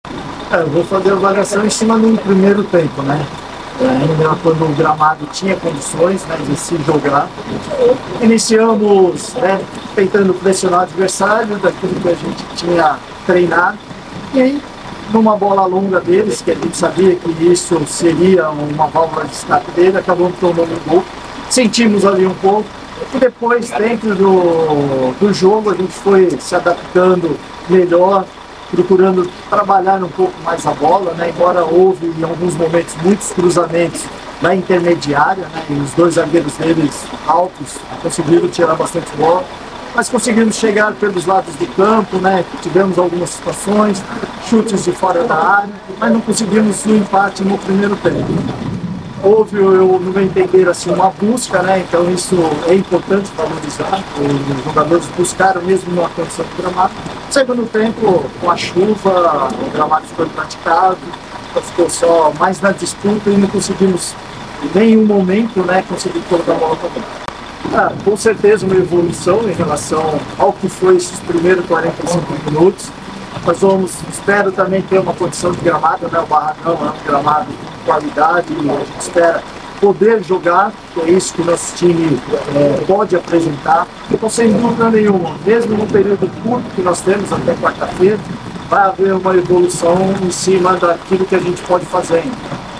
Sonoras Pós Jogo, Jequié x Juazeirense